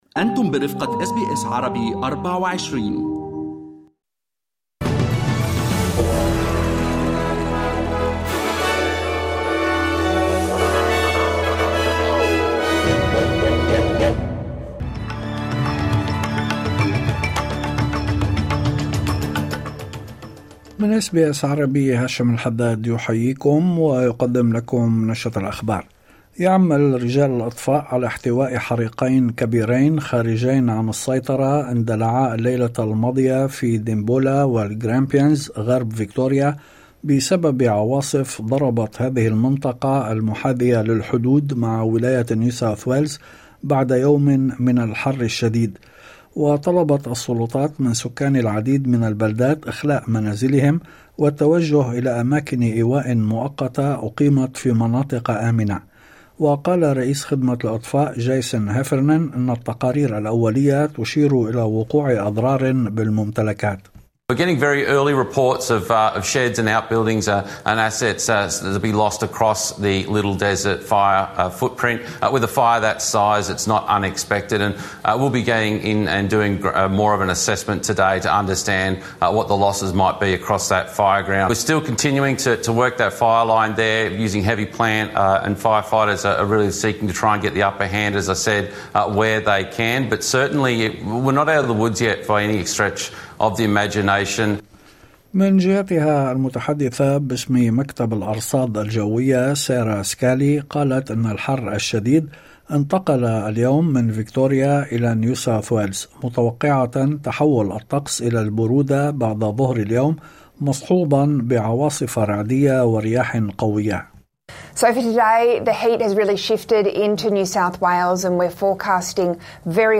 نشرة أخبار الظهيرة 28/01/2025